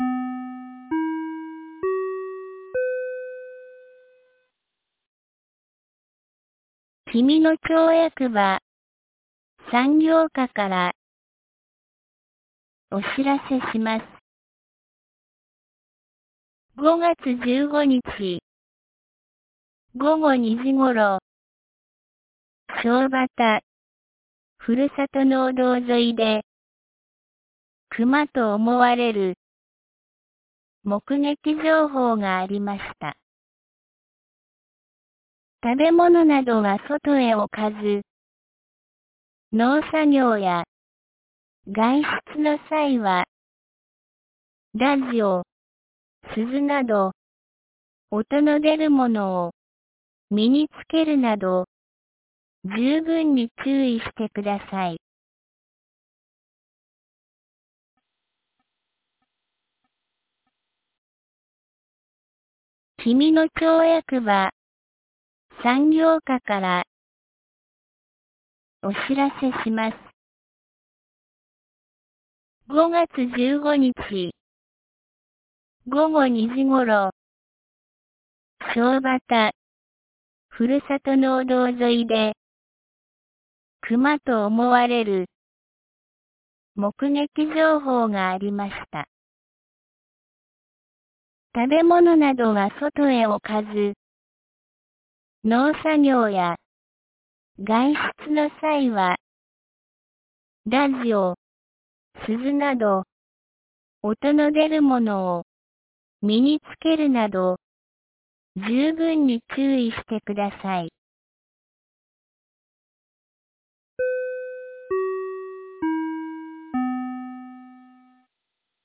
2021年05月16日 17時12分に、紀美野町より東野上地区へ放送がありました。